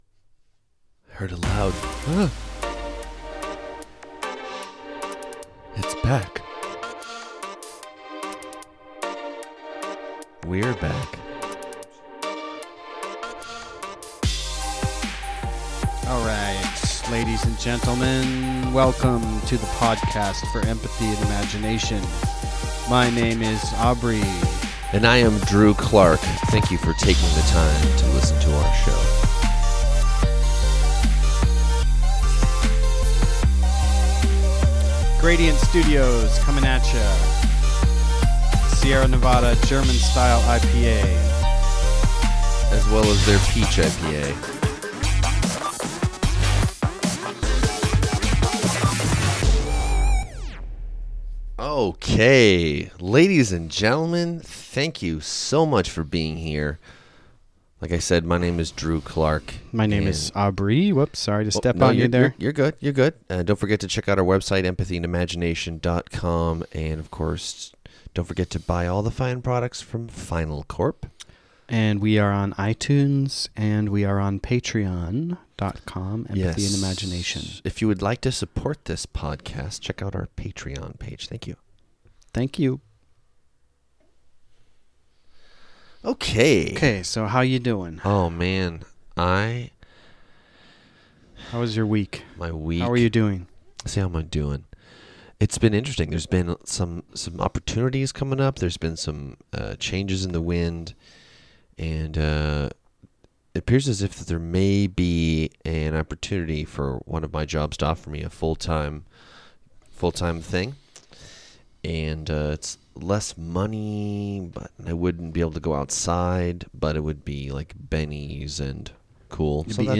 Pardon our low energy–we just got back from a 90-mile overnight bike camping trip which destroyed our wills and taints.